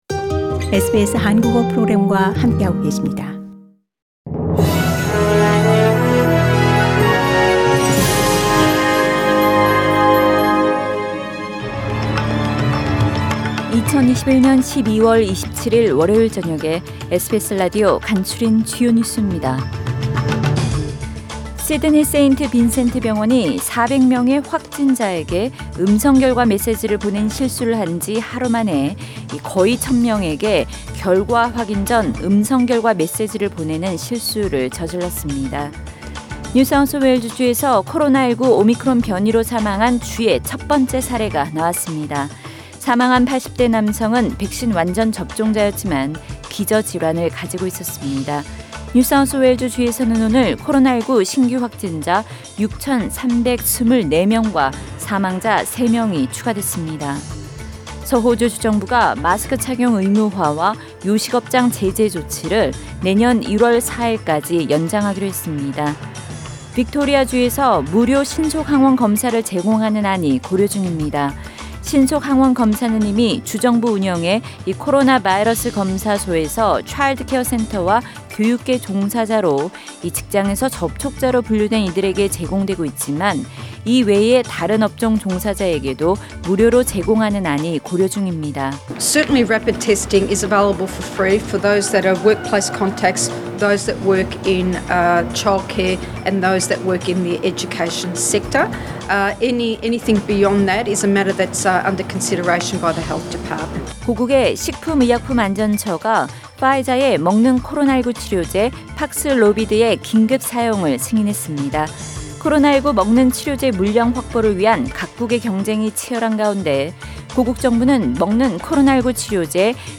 SBS News Outlines…2021년 12월 27일 저녁 주요 뉴스